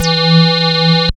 74.07 BASS.wav